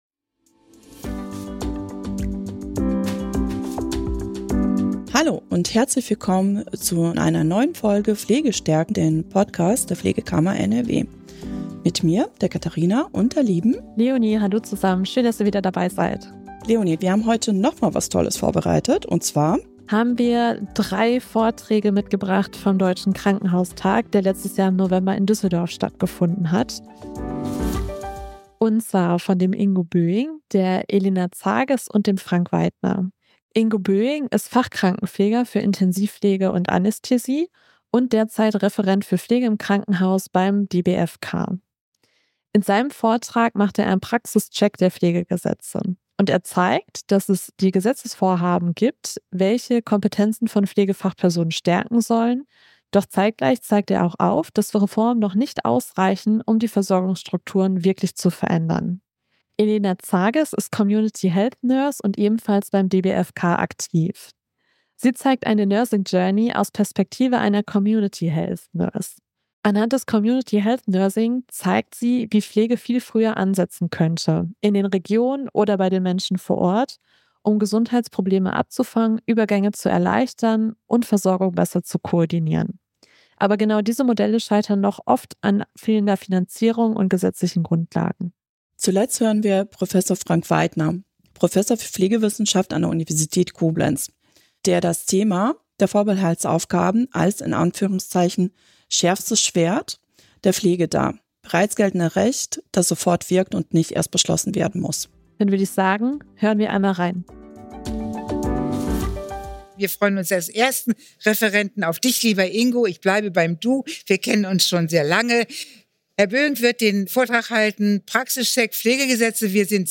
In dieser Folge vom Deutschen Krankenhaustag werfen drei Vorträge einen Blich auf aktuelle Reformen und die zukünftige Rolle der Pflege.